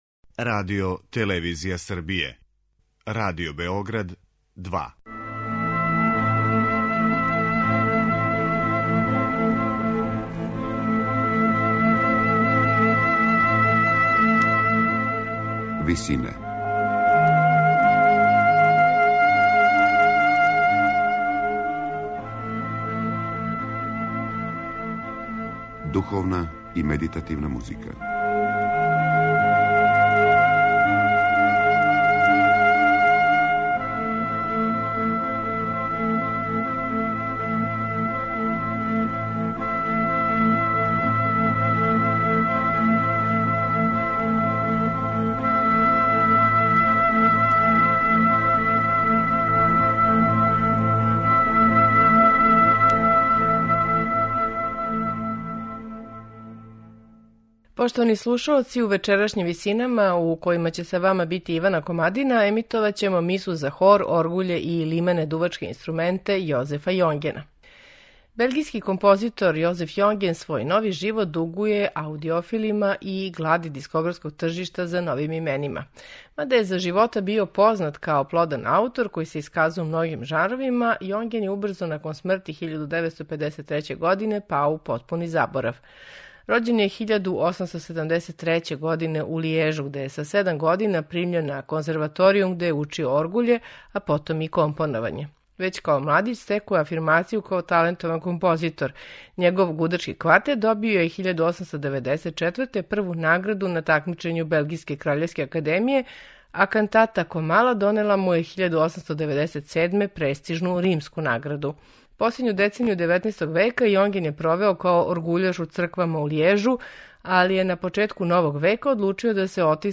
Слушаћете 'Мису за хор, оргуље и лимене дувачке инструменте', Јозефа Јонгена